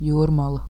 Jūrmala (Latvian: [ˈjuːrmala]
Lv-Jūrmala.ogg.mp3